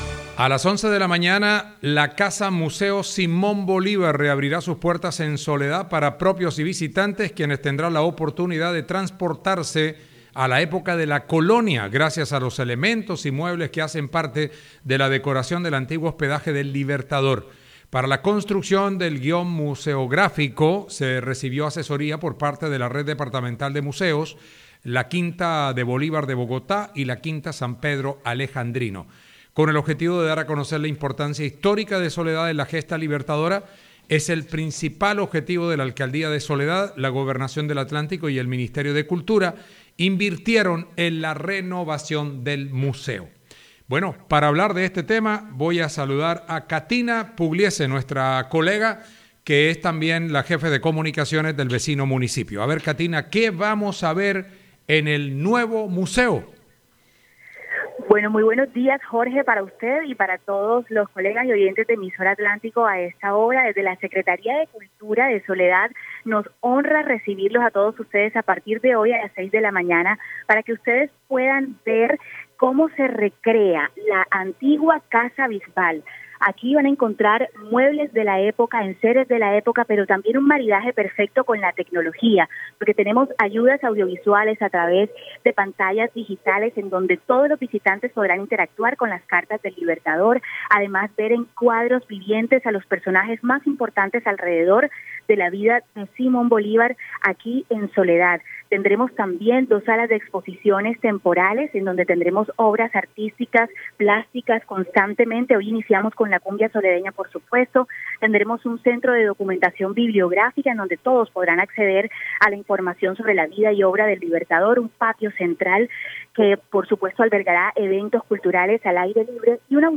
La Secretaria de Cultura, Katyna Pugliese, explicó detalles de los atractivos que tendrá el museo.